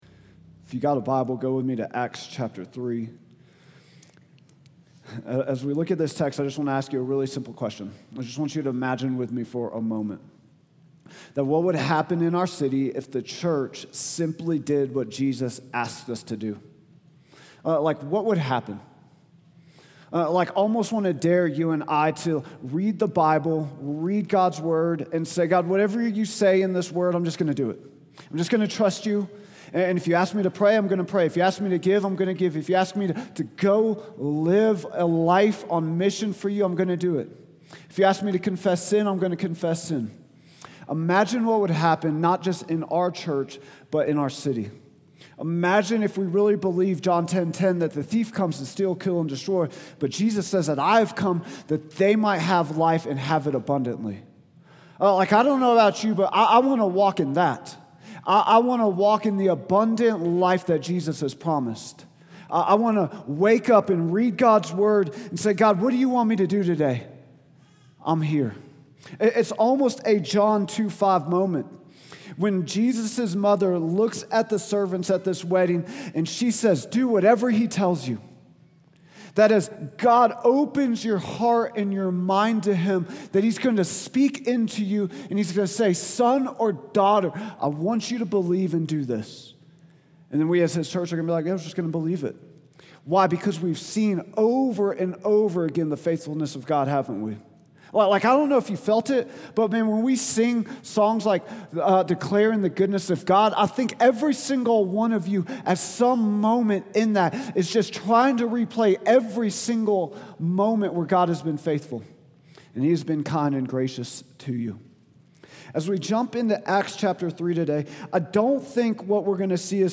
Vision & Values Meet Our Team Statement of Faith Sermons Contact Us Give Start of a Movement | Week 7 June 8, 2025